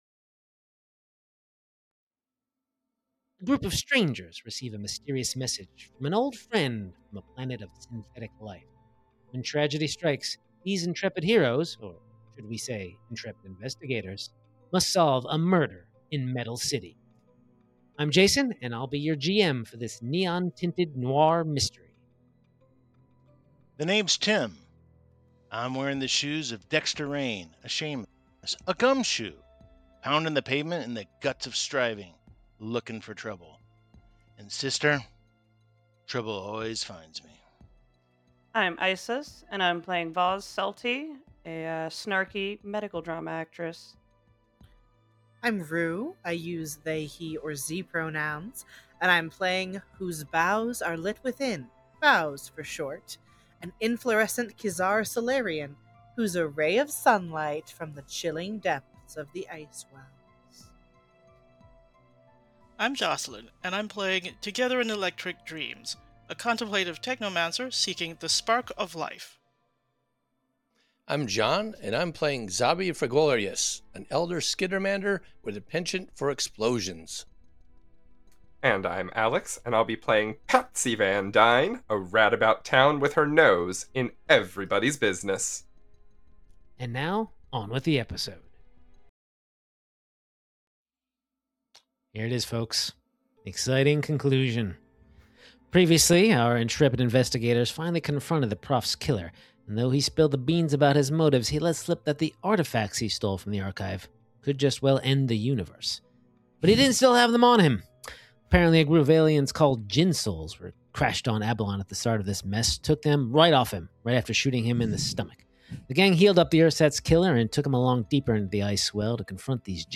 Starfinder 2nd Edition Actual Play Podcast